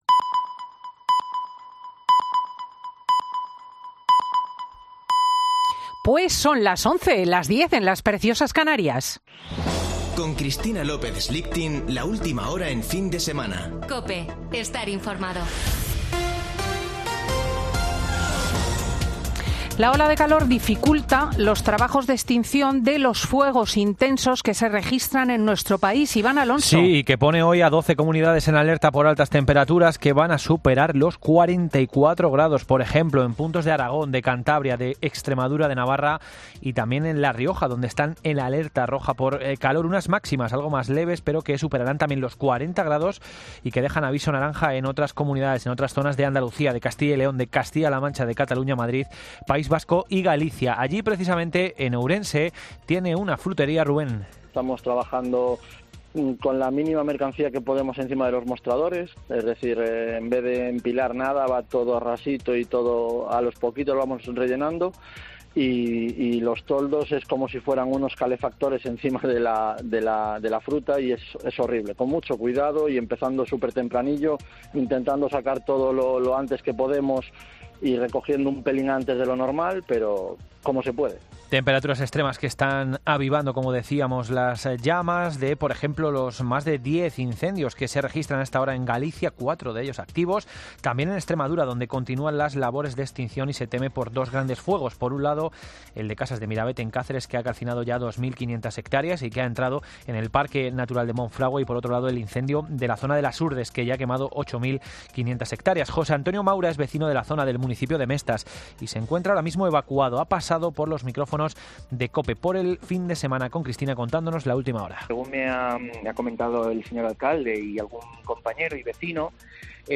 Boletín de noticias de COPE del 16 de julio de 2022 a las 11:00 horas